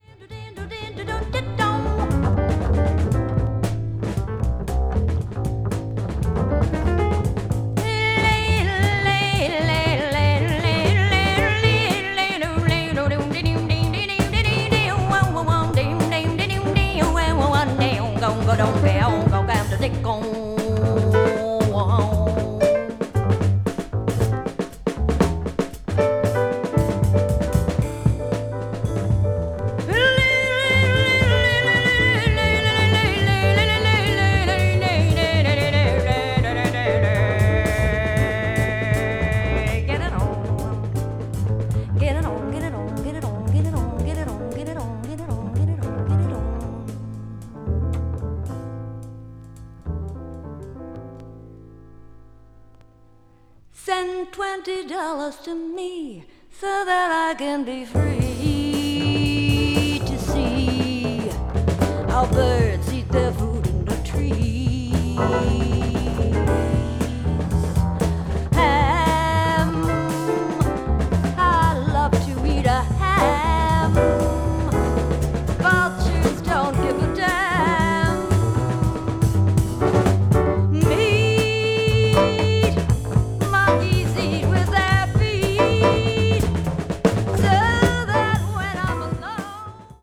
media : EX-/EX(わずかにチリノイズが入る箇所あり,A2:軽いプチノイズ3回あり)
ニューヨークのVanguard Studioでレコーディングされています。